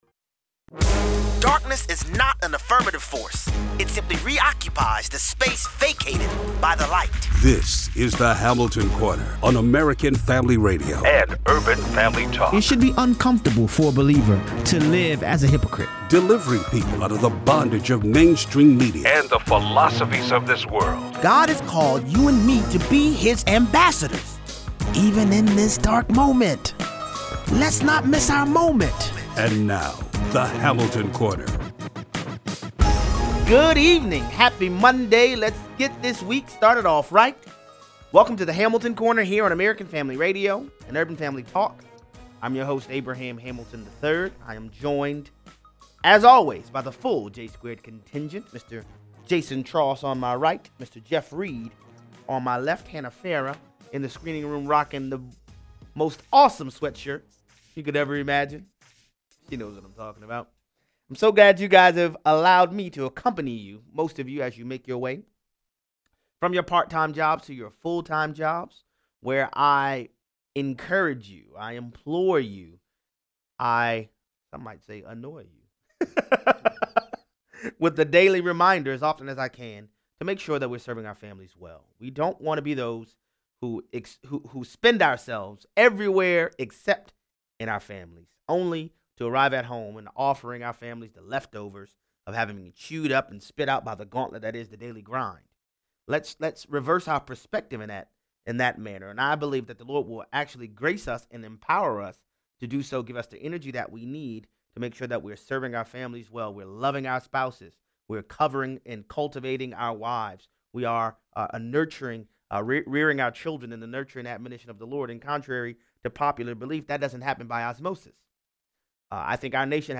A proper view of the King of Glory enables us to see our circumstances rightly. 0:18 - 0:35: Bill De Blasio’s wife can’t account for $853 million NYC tax payer dollars. In a display of bi-partisan regressivism, the 115th Congress passed a $900 Billion “Farm Bill” just as 2018 came to an end. 0:38 - 0:55: Thanks to Paul Ryan, the “Farm Bill” included a procedural vote to limit debate on U.S. involvement in a war in Yemen. Callers weigh in.